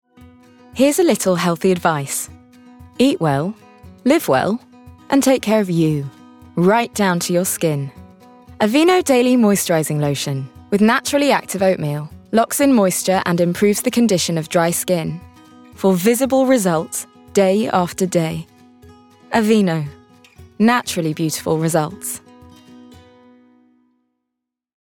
Aveeno - Calm, Relatable, Relaxed